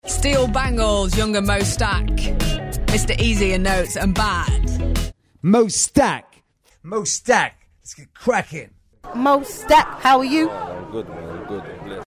読み方
モースタック、モスタック、モ・スタック　（モの後に溜めがある。モゥスタックの発音。）
BBC Raido 1・インタビューなどの発音